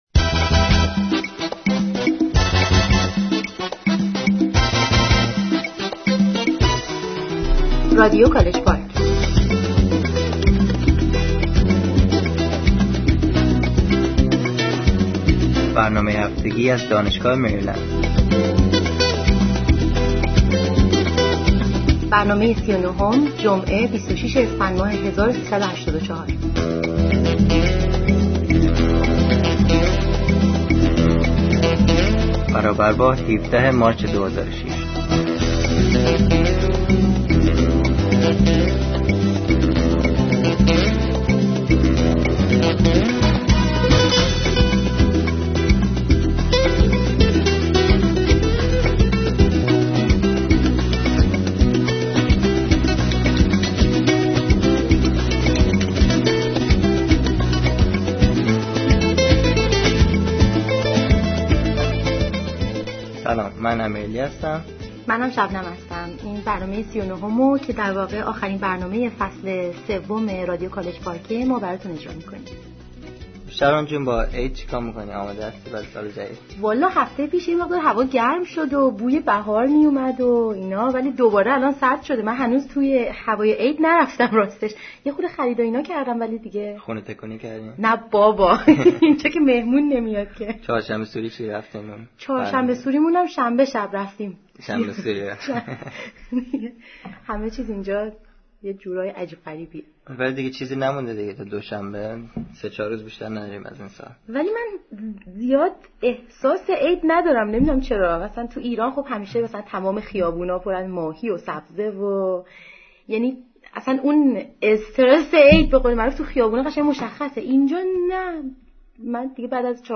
Comic Play